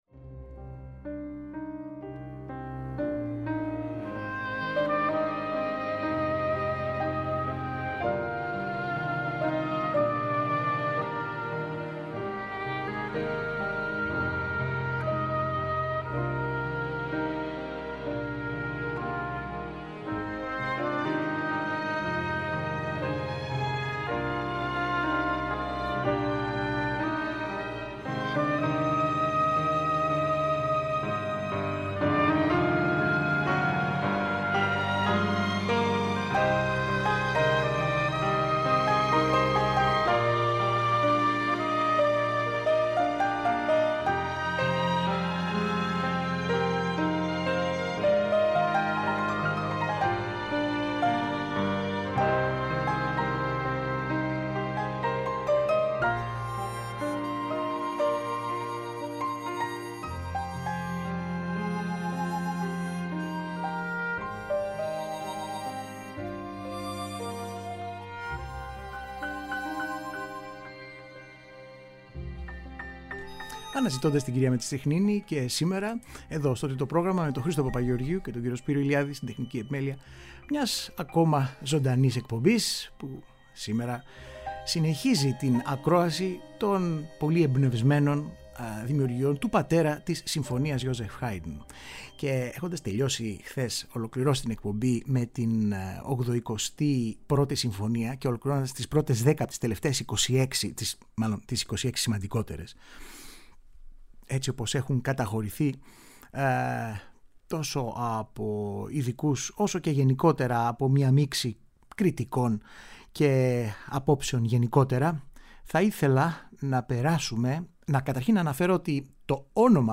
Οι συμφωνίες του Josef Haydn- μέρος 4ο